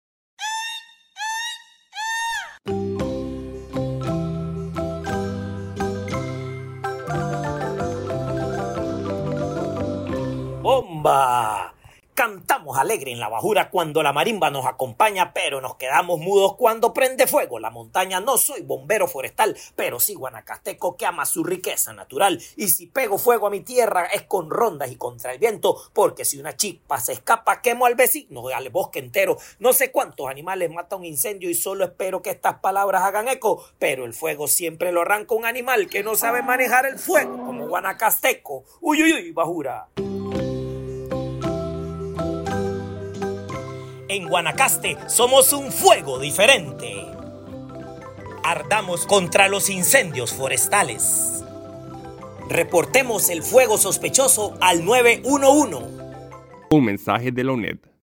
BOMBA.mp3